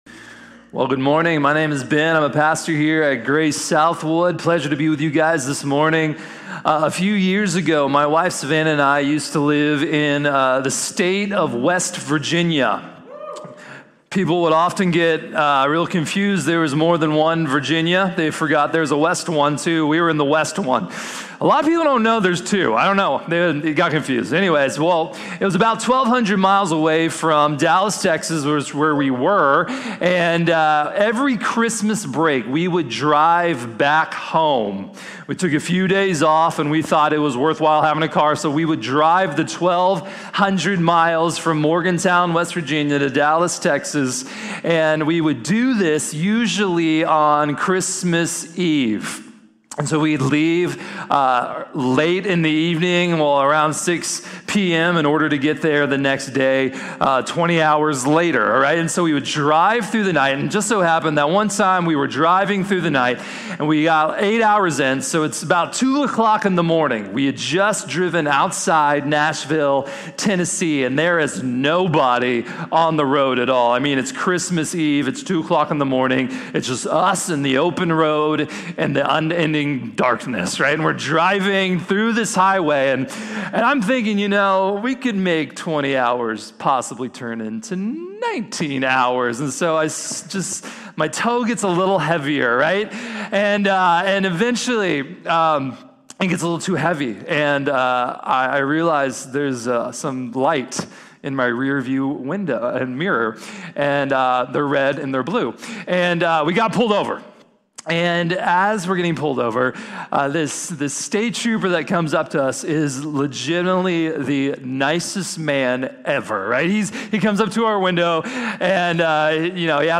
El sufrimiento de Job | Sermón | Iglesia Bíblica de la Gracia